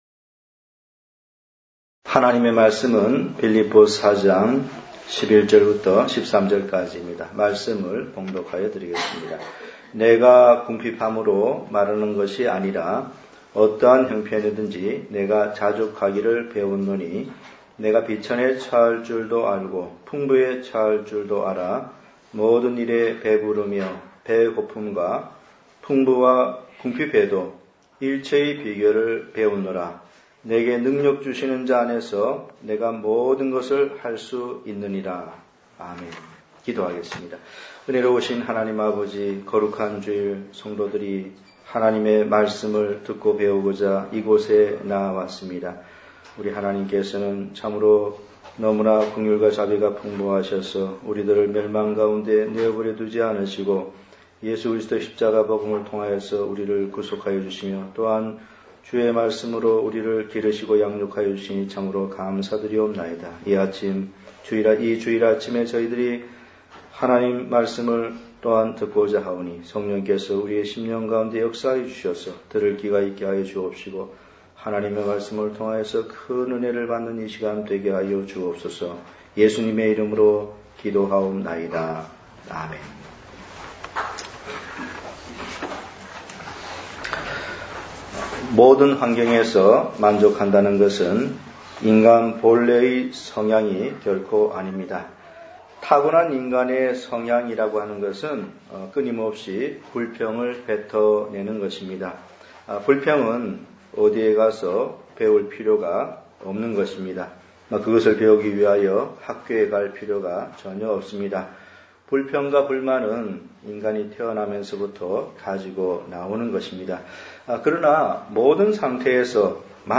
단편설교